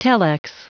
Prononciation du mot telex en anglais (fichier audio)
Prononciation du mot : telex